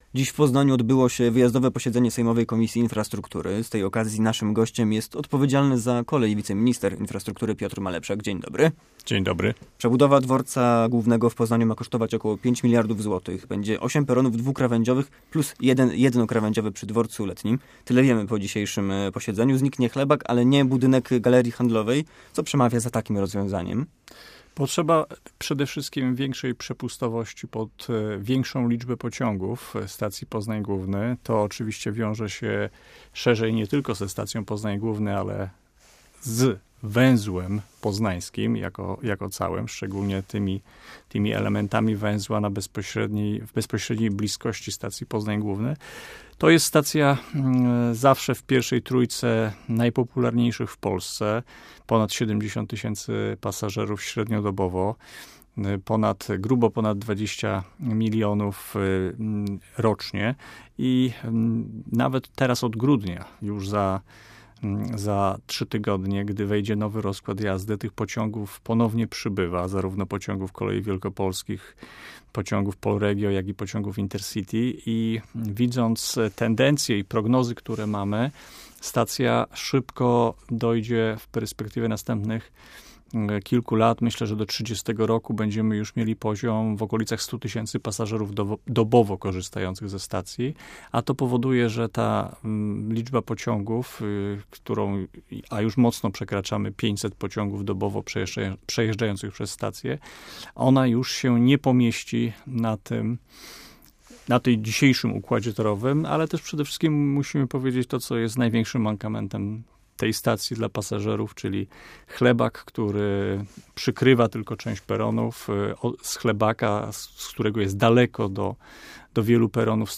Wiceminister Infrastruktury Piotr Malepszak odpowiada na pytania o modernizację Poznańskiego Węzła Kolejowego oraz plany dotyczące CPK i Kalisza.